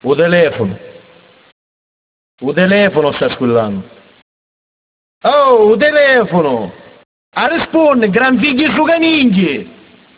Rispondi! - in siciliano (wav)